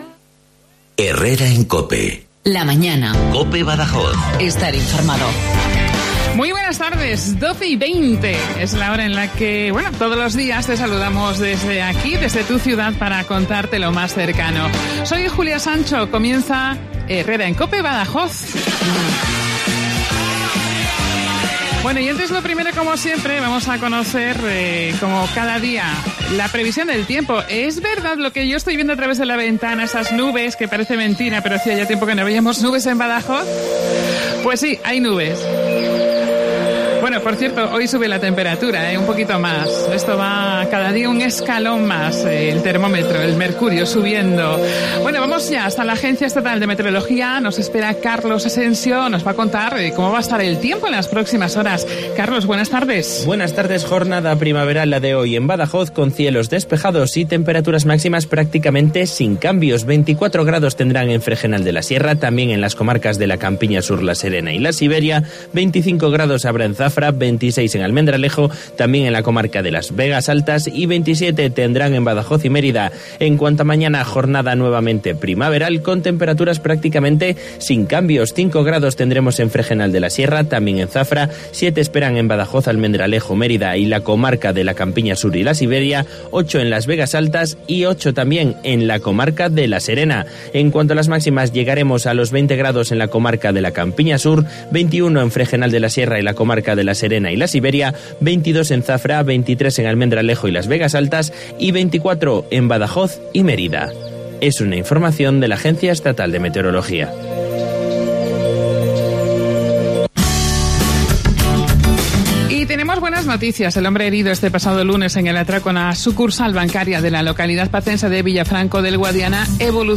Hemos pasado un ratito maravilloso y en buena compañía y además, al final del programa, con música en directo.